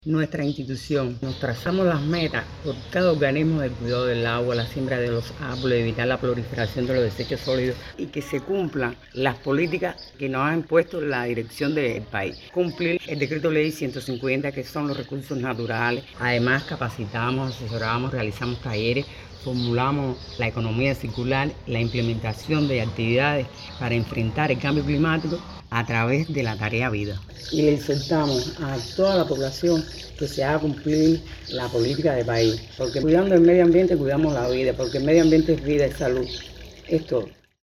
PEDRO BETANCOURT.- A la salvaguarda del medio ambiente y la responsabilidad de los seres humanos en esta tarea se dedicó un ameno conversatorio efectuado en la biblioteca Manuel Navarro Luna, de este municipio.